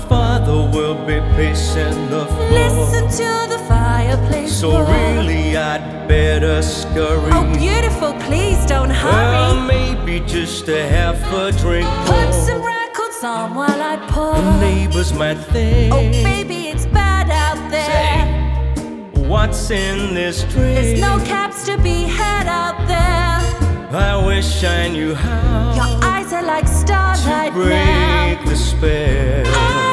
• Easy Listening